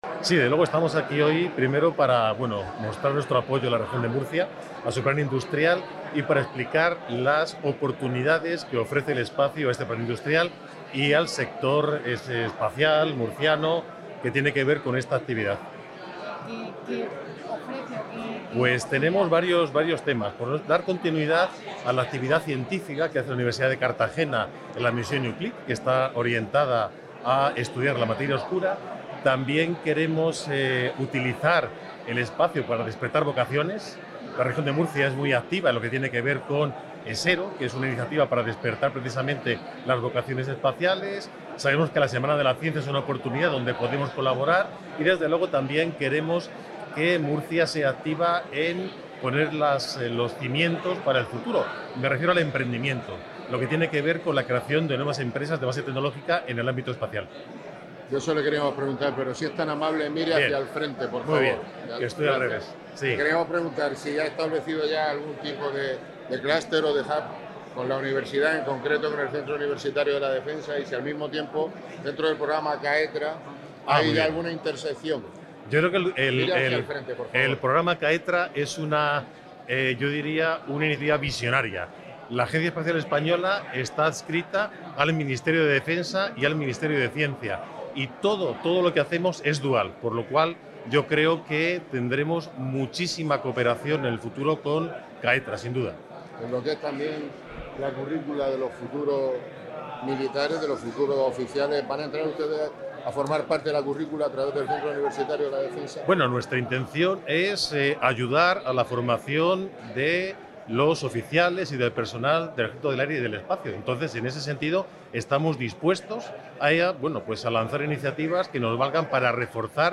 Audio: Declaraciones de la alcaldesa, Noelia Arroyo, y el presidente de la Comunidad Aut�noma, Fernando L�pez Miras (MP3 - 3,66 MB)
La Mesa de Formación para las Nuevas Industrias sigue creciendo y ya ha incorporado a la práctica totalidad de los centros de formación del municipio, alcanzando un total de 135 miembros, entre instituciones educativas y empresas del sector industrial, según ha anunciado este lunes 10 de marzo la alcaldesa de Cartagena, Noelia Arroyo, en el Foro del Conocimiento Plan Industrial, celebrado en la Universidad Politécnica de Cartagena (UPCT).